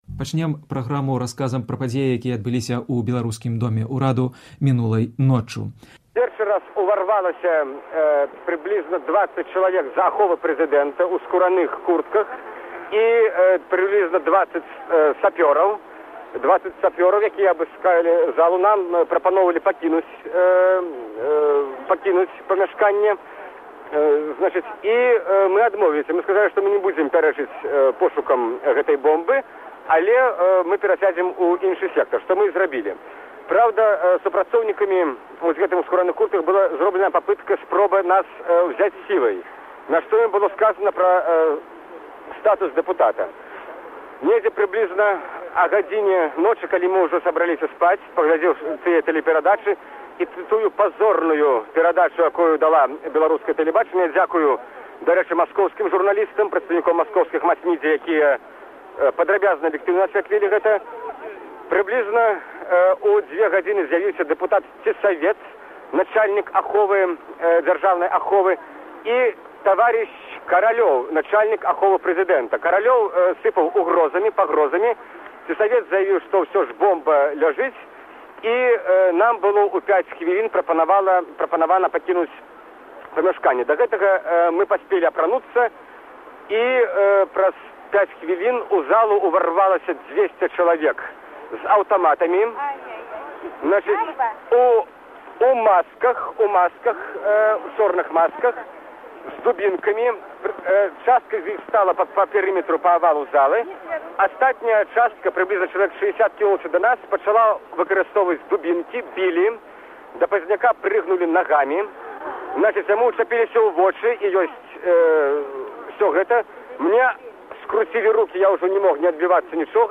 1995. Інтэрвію зь Зянонам Пазьняком на другі дзень пасьля зьбіцьця дэпутатаў